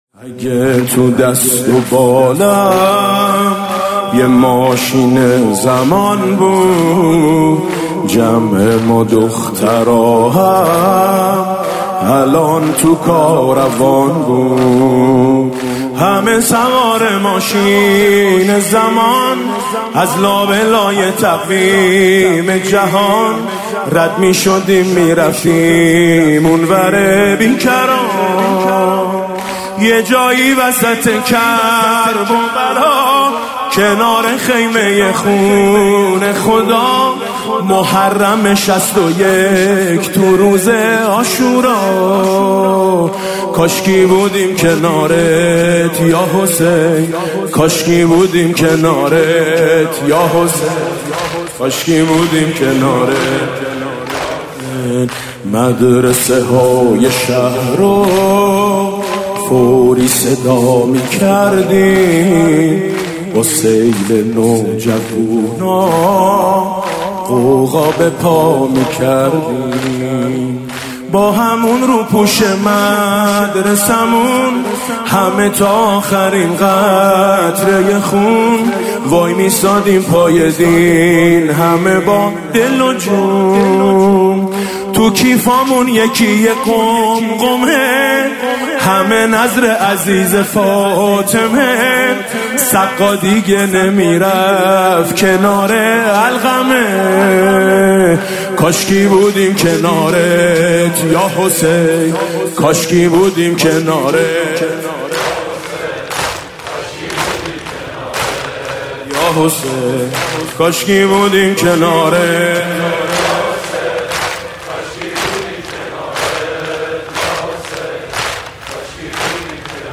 نوحه - ماشین زمان